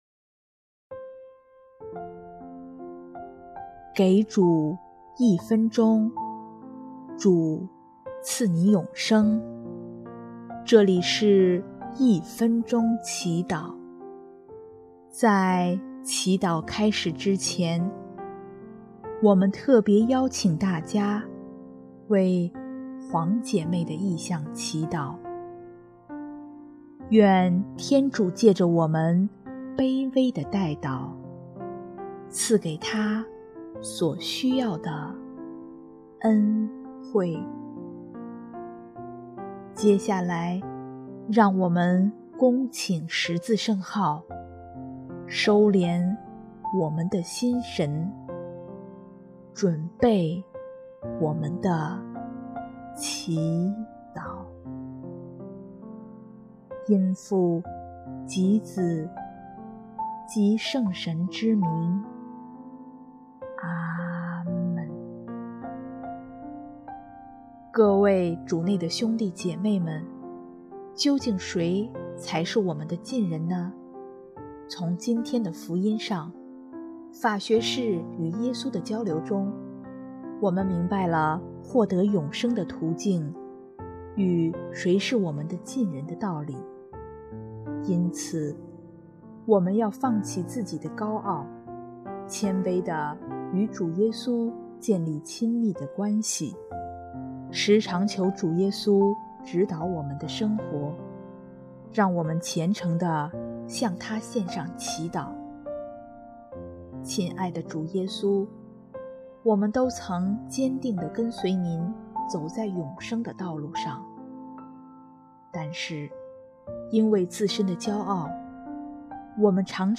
【一分钟祈祷】|10月9日 学基督悲悯之心，行爱德永走天路！